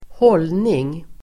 Uttal: [²h'ål:ning]